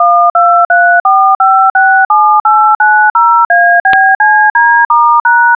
Le but de cette partie est l'analyse spectrale des fréquences du code DTMF (tonalité téléphonique) et la construction du sonogramme associé.